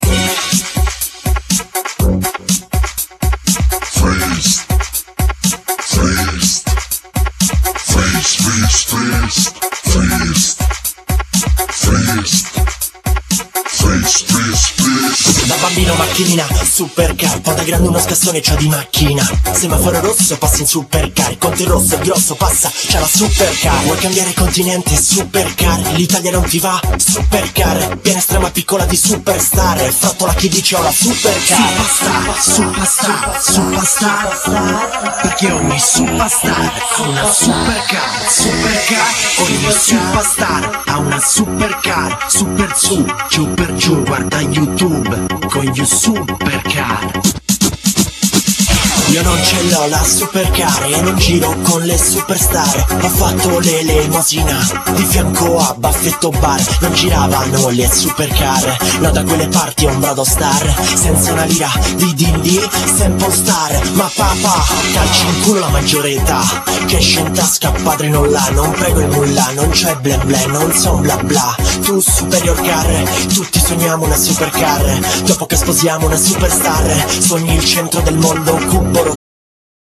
Genere : Pop - Rap